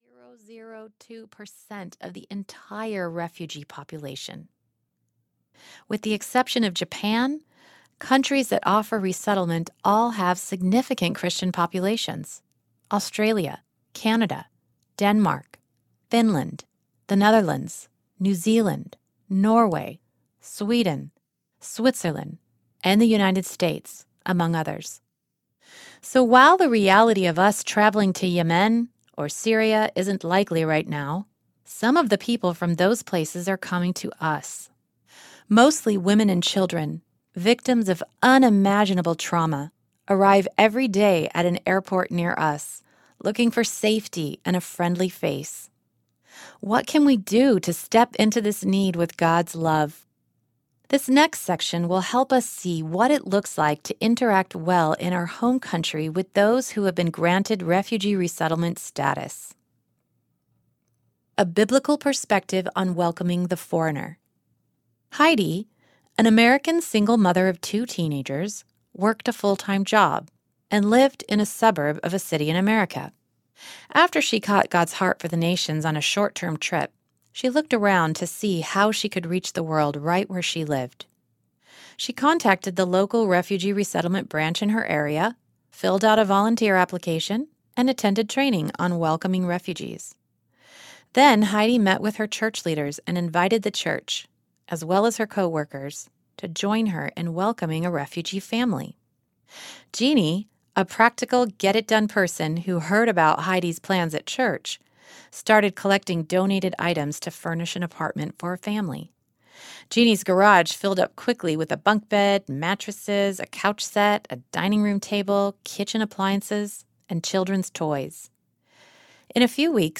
Across the Street and Around the World Audiobook
– Unabridged